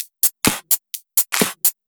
Index of /VEE/VEE2 Loops 128BPM
VEE2 Electro Loop 266.wav